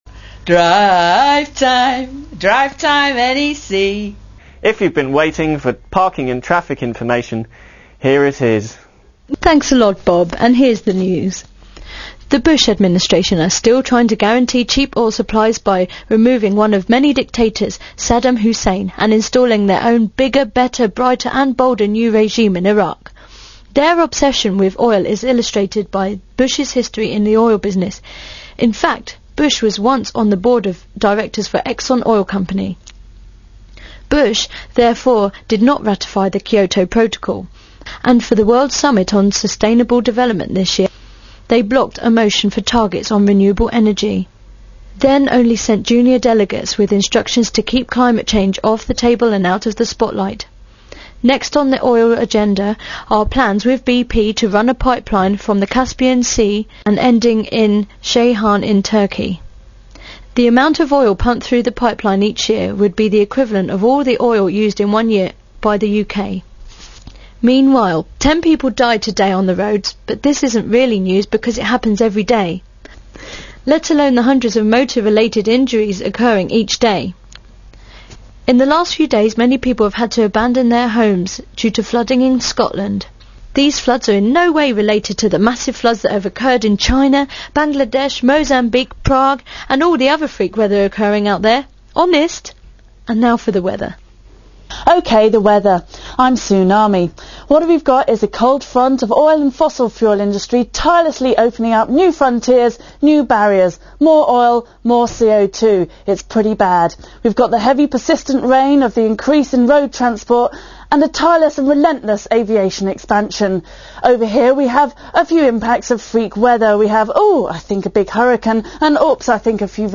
A thirty odd minute radio show created for and broadcast at, the NEC Car Show...
It was broadcast continuously on 102.5mhz FM during the protests and played through a 350 watt PA at the main enterance to the show.
It is now 28 minutes long and the MP3 file is just 6.5mb in mono thus much quicker to download...